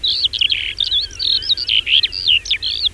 skylark.wav